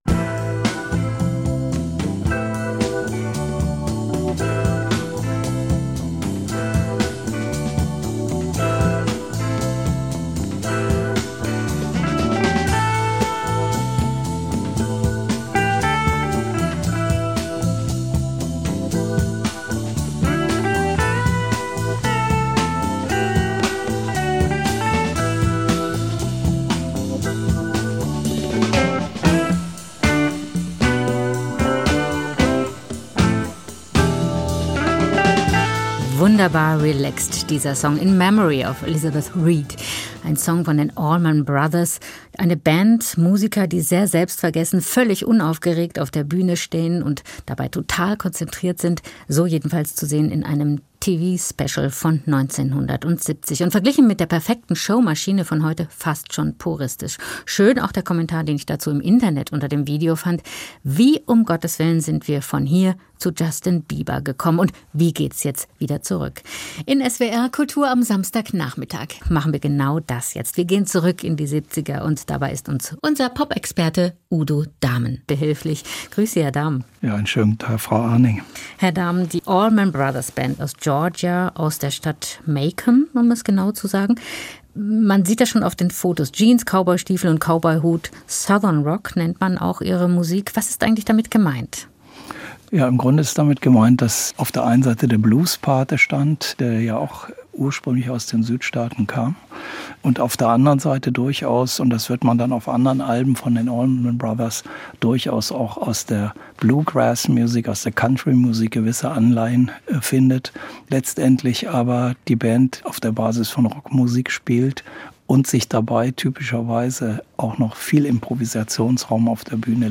Gespräch mit